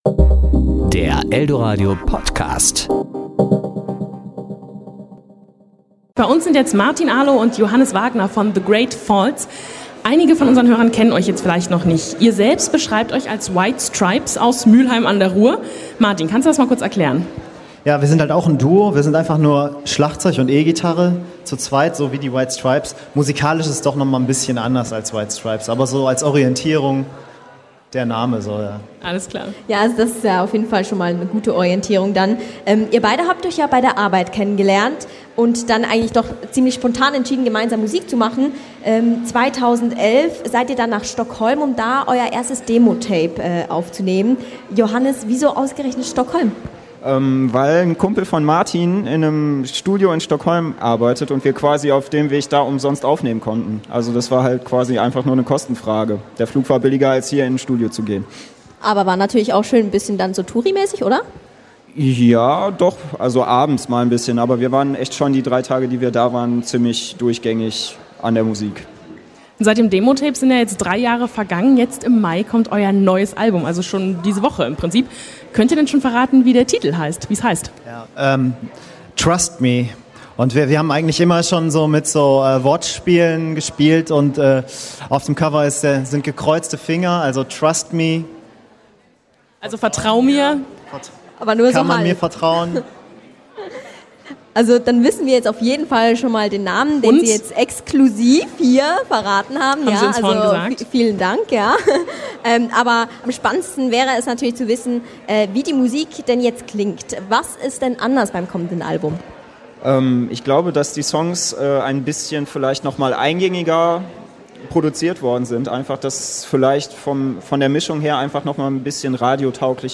The Great Faults im Interview
Anlässlich unserer Toaster-Livewoche waren sie bei uns in der Food Fakultät und haben über neue Musik gequatscht.
Serie: Interview
podcast_interview_great_faulzs.mp3